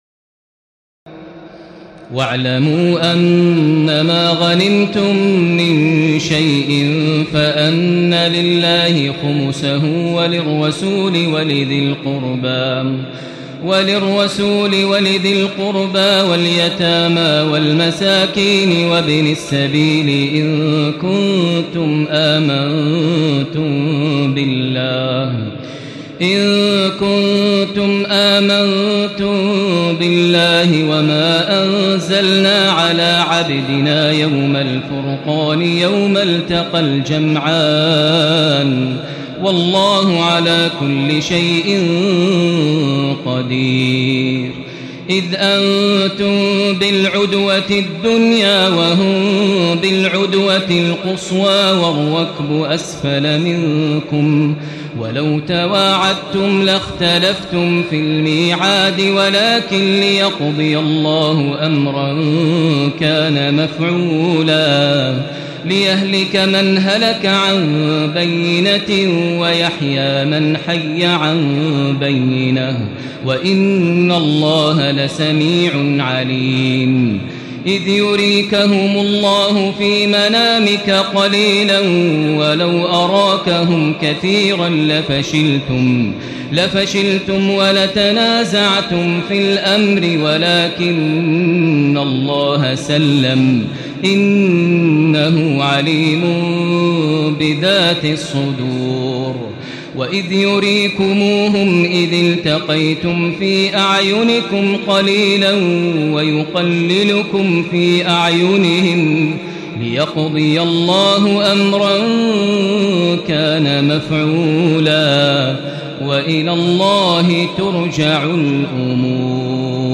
تراويح الليلة التاسعة رمضان 1436هـ من سورتي الأنفال (41-75) و التوبة (1-33) Taraweeh 9 st night Ramadan 1436H from Surah Al-Anfal and At-Tawba > تراويح الحرم المكي عام 1436 🕋 > التراويح - تلاوات الحرمين